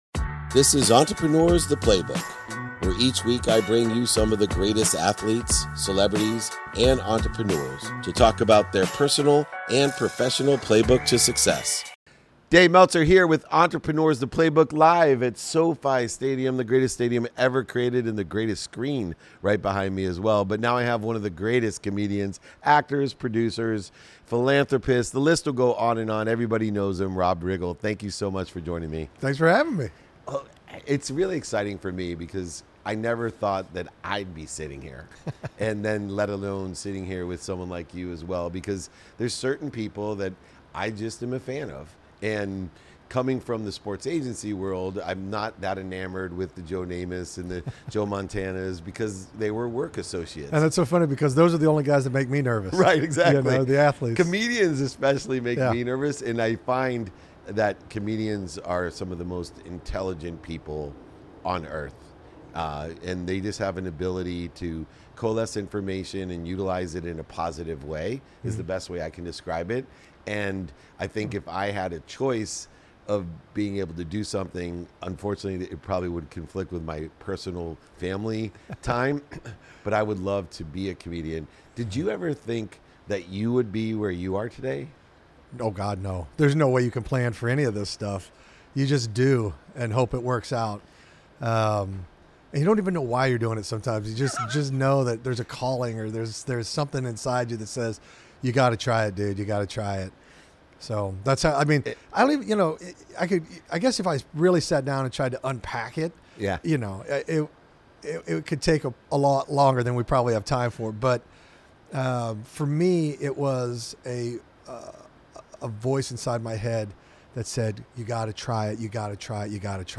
In today’s episode, we revisit a conversation from late 2022, where I sit down with Rob Riggle, a versatile actor, comedian, writer, producer, director, and former marine. We chat live at Sofi Stadium, exploring Rob's journey from his early comedic days to becoming a household name. Rob shares his experiences with rejection in the arts, his unique comedic style, and his love for comedic acting. We also discuss the importance of resilience, personal growth, and the joy of making people laugh.